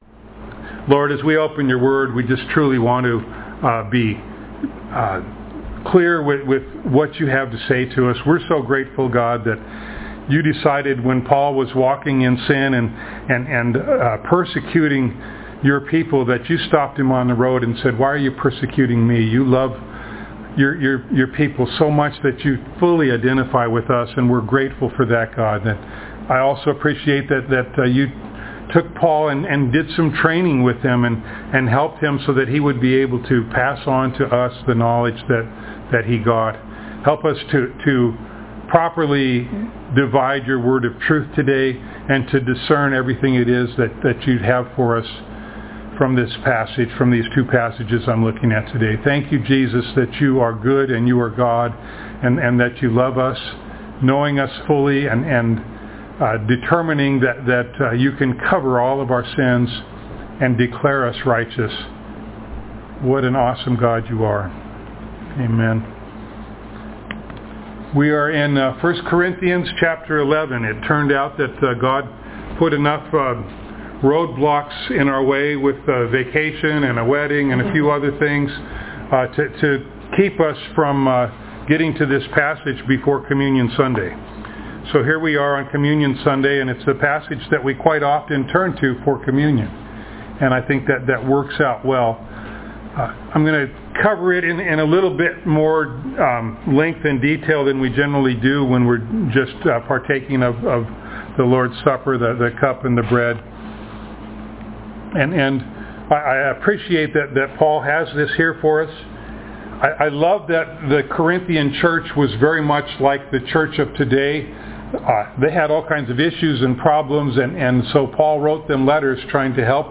1 Corinthians Passage: 1 Corinthians 11:23-34 Service Type: Sunday Morning Download Files Notes « I Do Not Praise You!